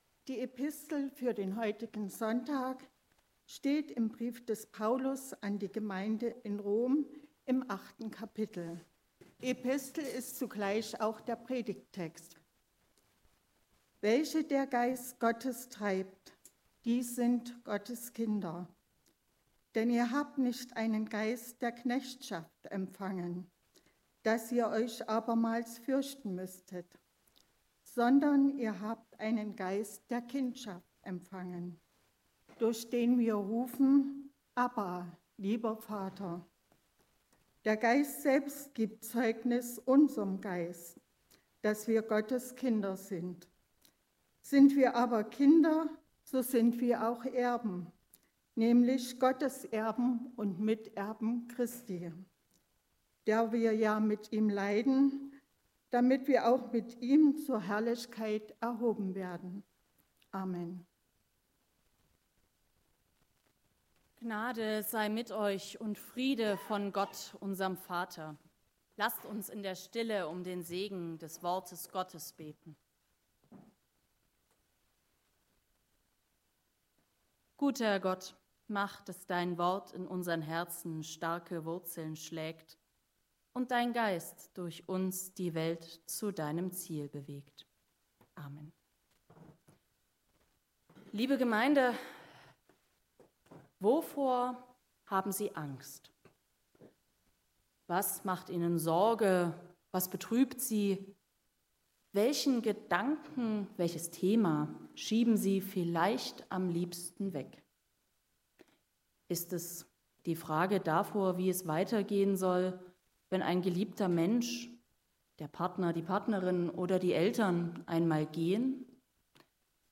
14-17 Gottesdienstart: Predigtgottesdienst Obercrinitz Wenn man in die Nachrichten schaut